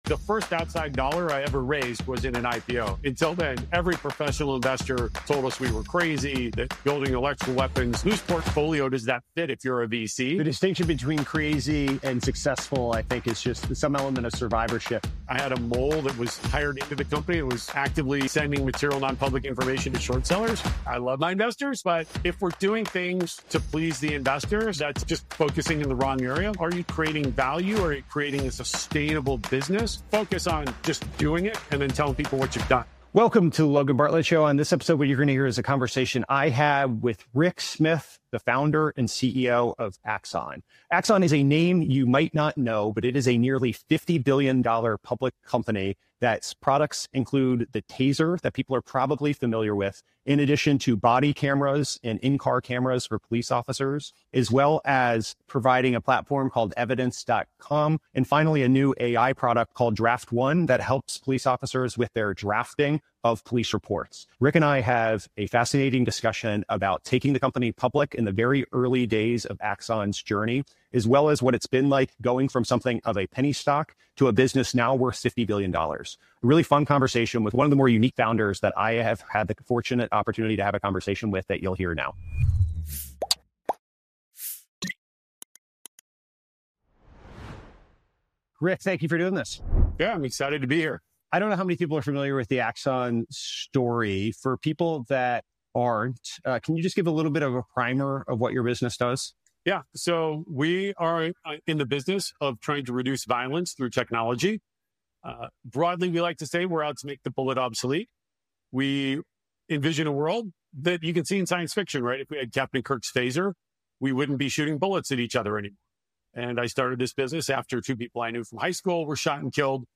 It’s a candid and compelling conversation with one of the most unconventional founders in tech.